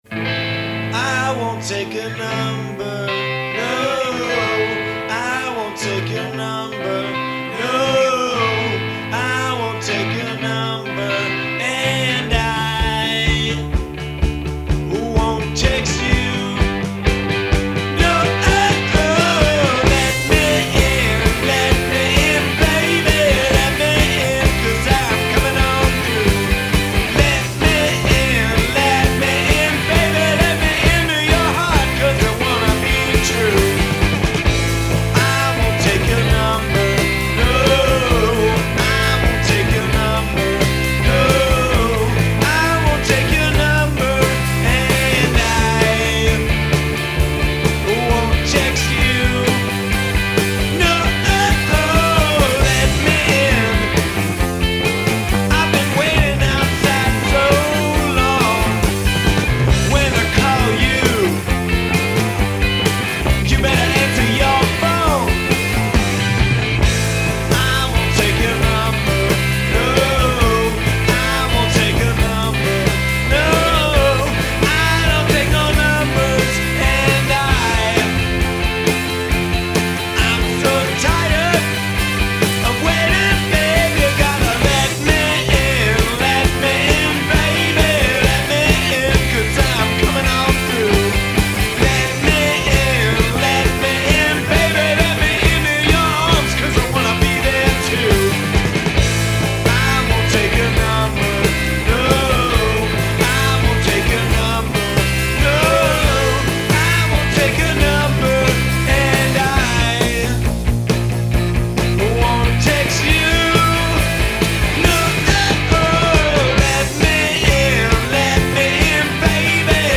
garage three-piece